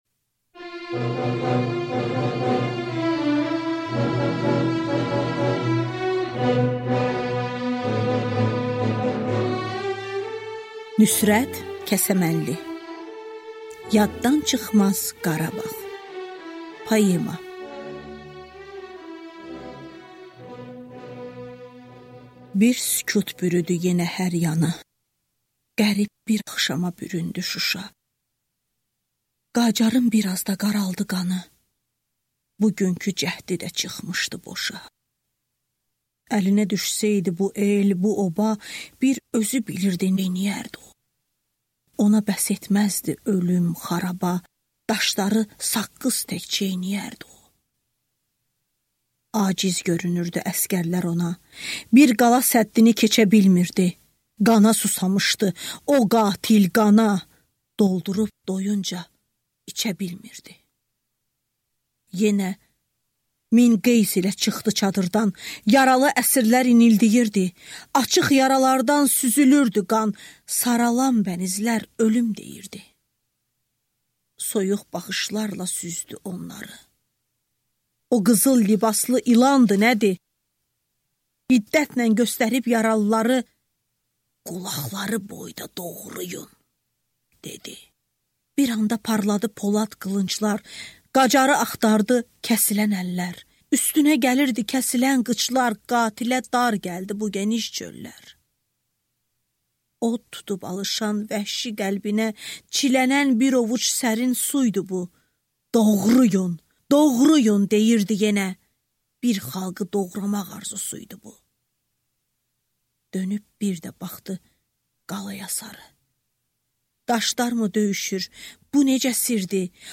Аудиокнига Nüsrət Kəsəmənlinin poema və şerləri | Библиотека аудиокниг